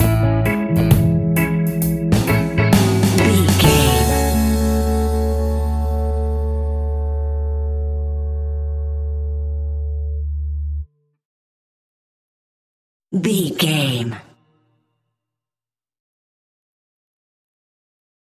A chilled and relaxed piece of smooth reggae music!
Aeolian/Minor
F#
off beat
drums
skank guitar
hammond organ
percussion
horns